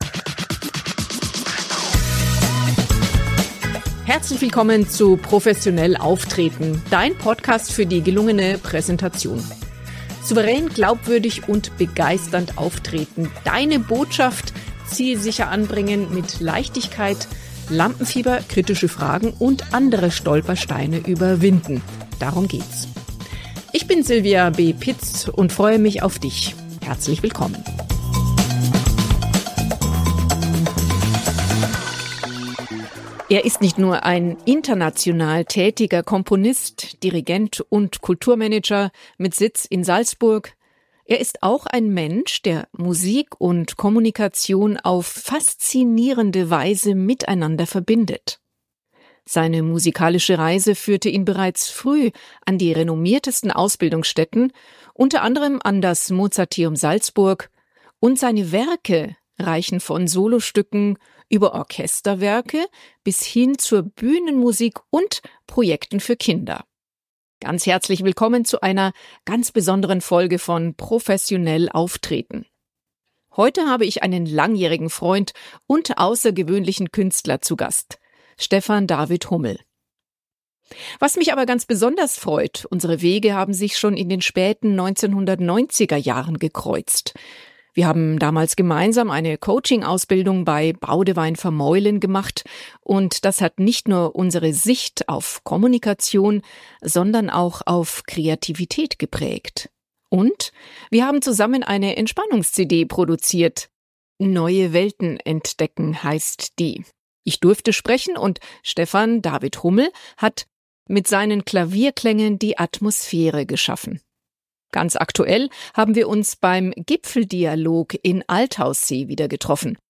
Dann hör rein in unser inspirierendes Gespräch voller persönlicher Geschichten, Perspektivwechsel und ganz viel Musik!